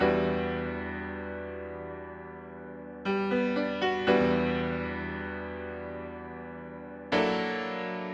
12 Piano PT2.wav